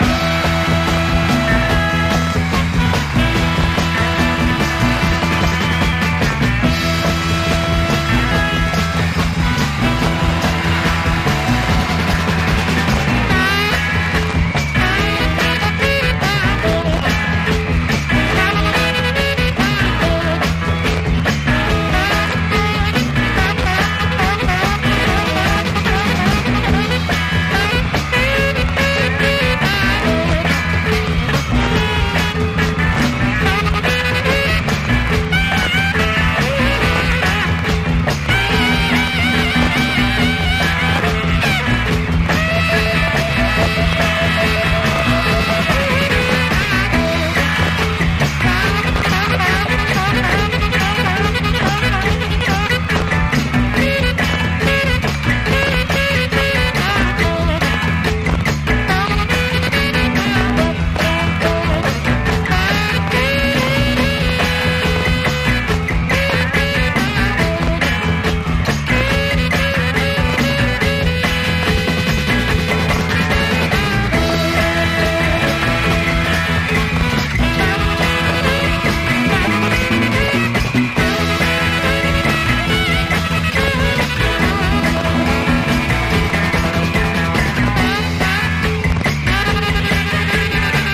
汗だくファンキー・ソウル/R&Bシャウター！